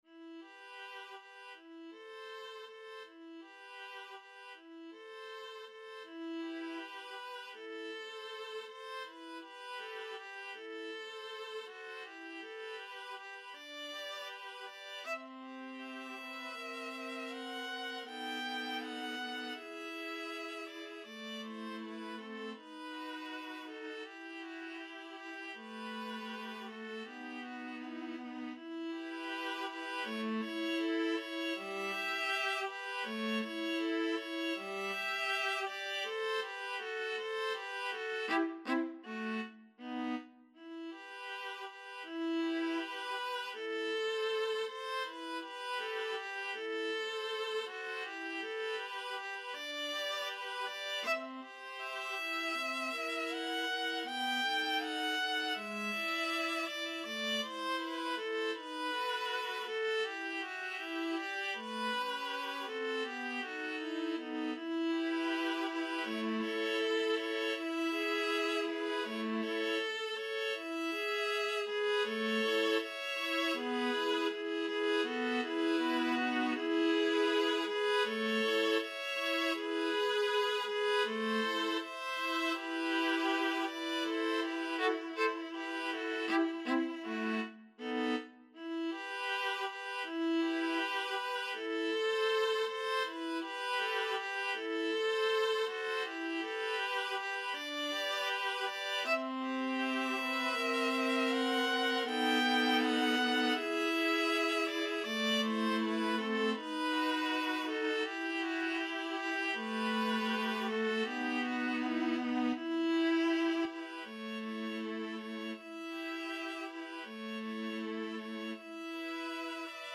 Free Sheet music for Viola Trio
E minor (Sounding Pitch) (View more E minor Music for Viola Trio )
~ = 100 Andante
2/4 (View more 2/4 Music)
Classical (View more Classical Viola Trio Music)